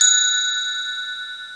glckensp.mp3